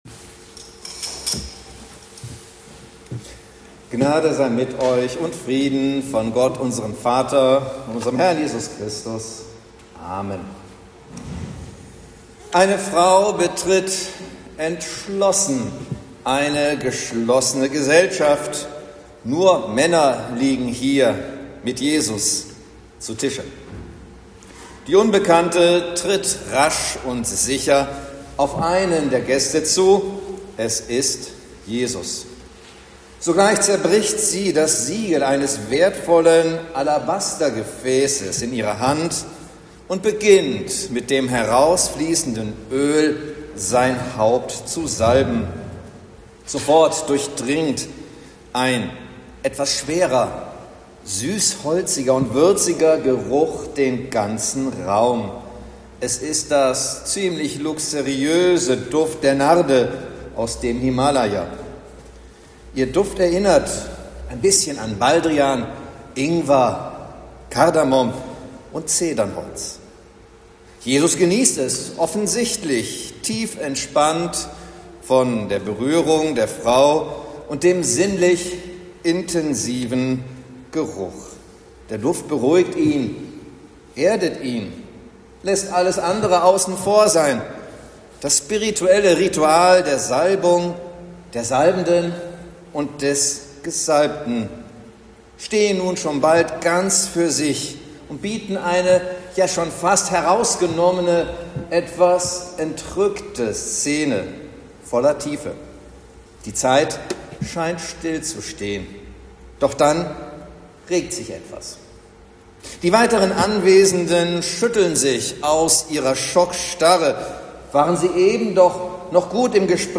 Predigt zum Palmsonntag